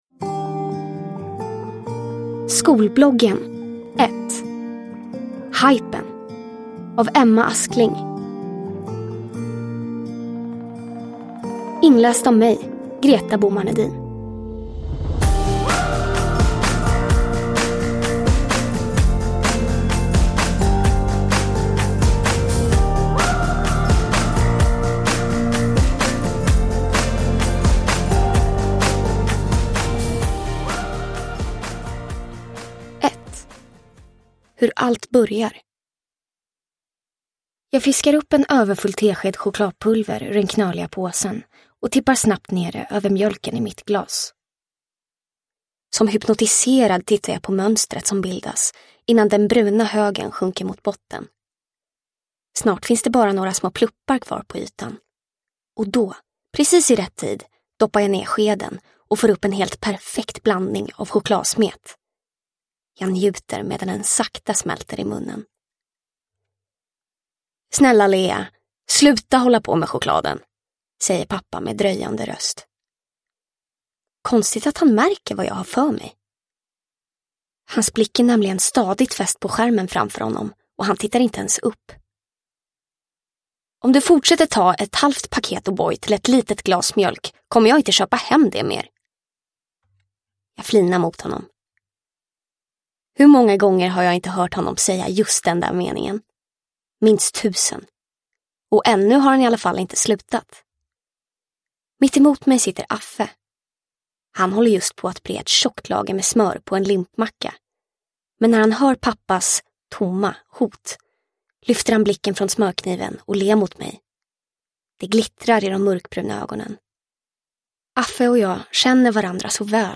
Hajpen – Ljudbok – Laddas ner